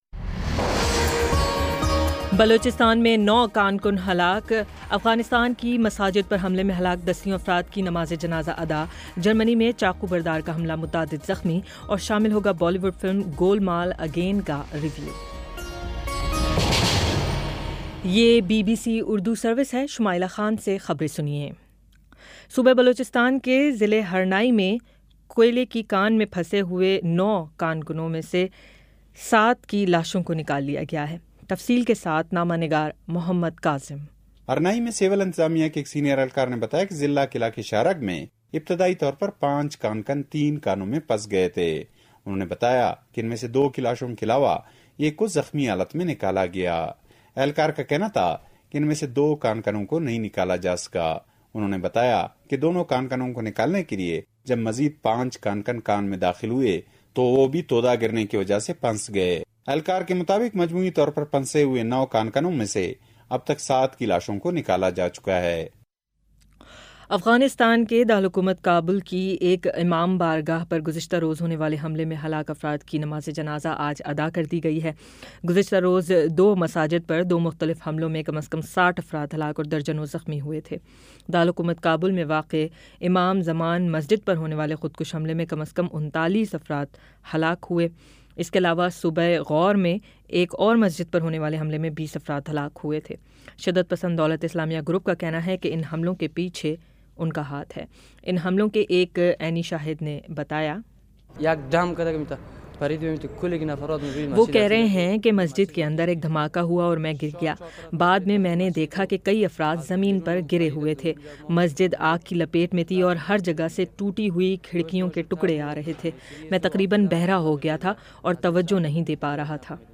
اکتوبر 21 : شام پانچ بجے کا نیوز بُلیٹن